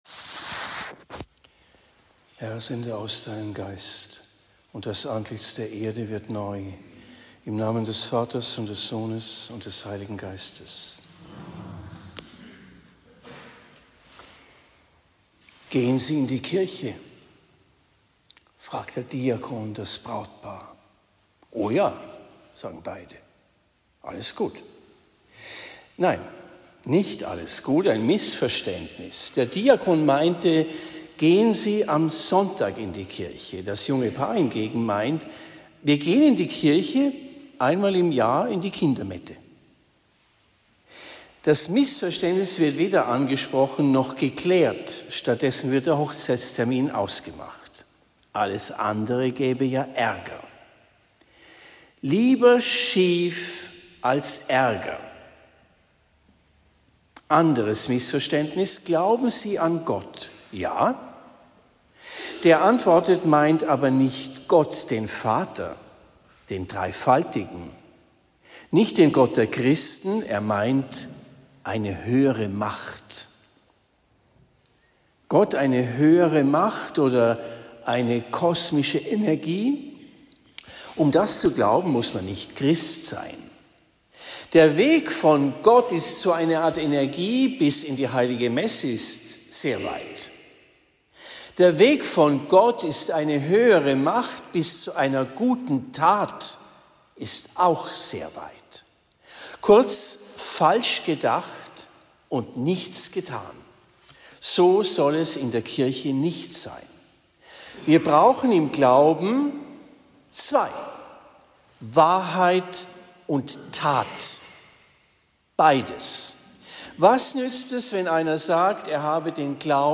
Predigt in Bischbrunn am 15. September 2024